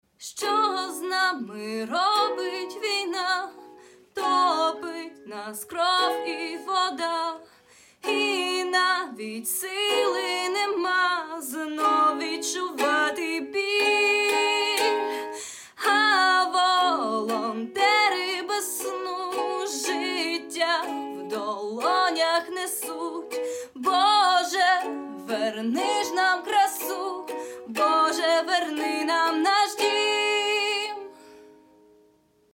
Мецо-сопрано